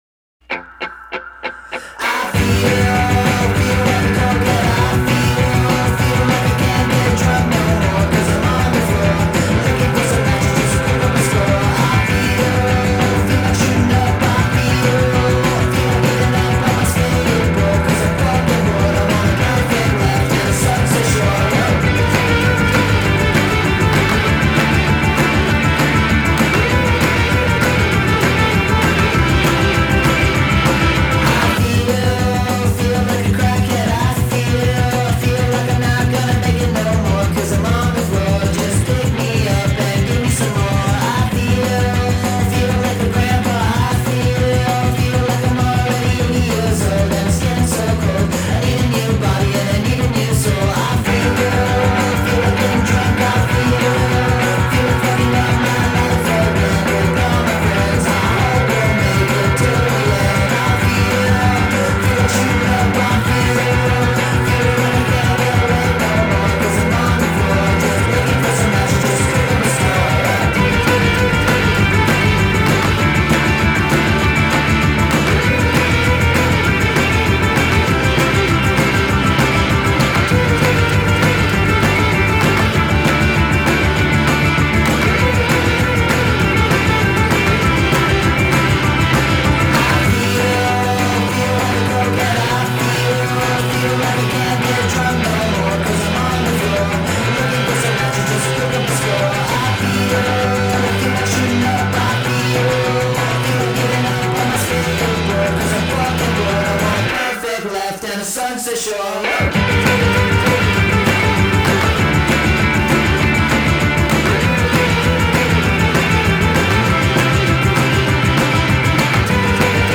LA based dudes who bring surf punk back!